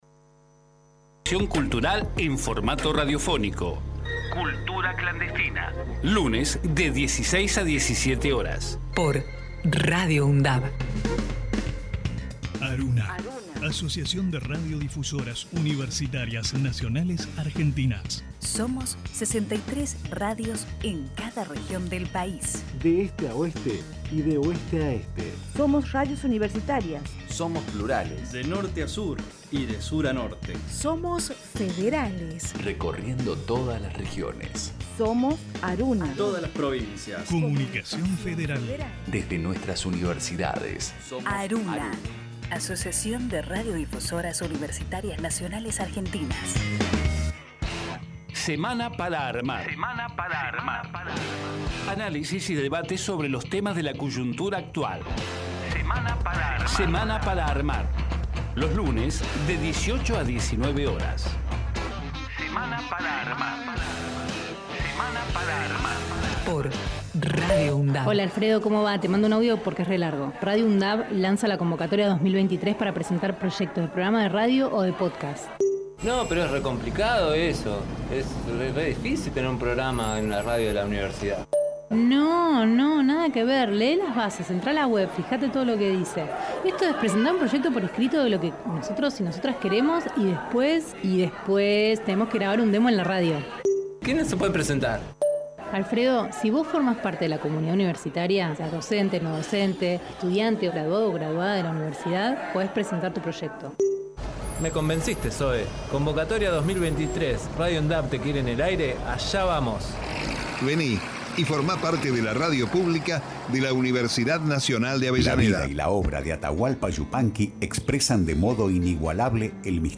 Compartimos la entrevista